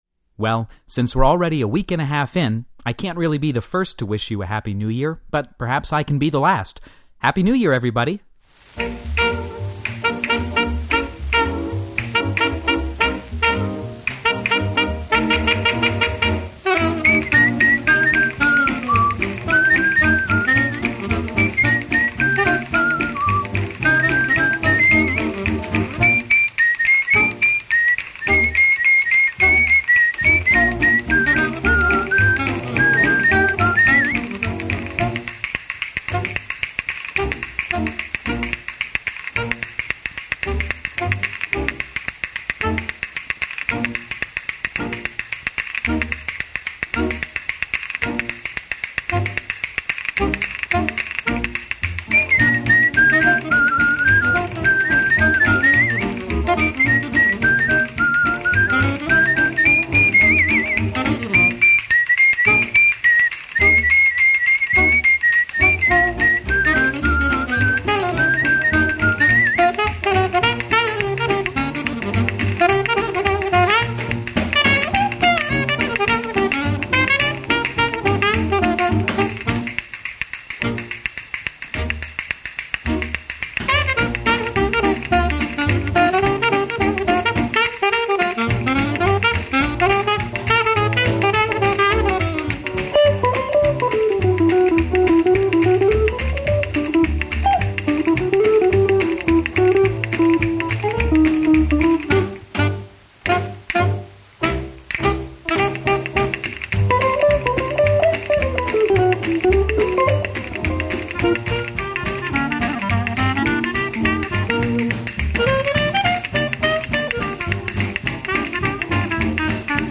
old-time music
dance music
early Dixieland bands